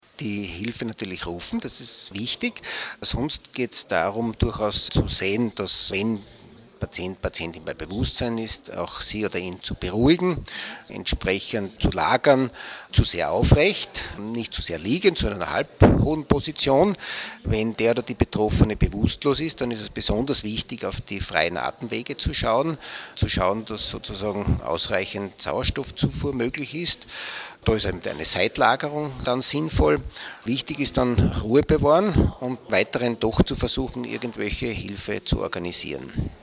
Pressekonferenz zum Thema integrierte Versorgung von Schlaganfallpatienten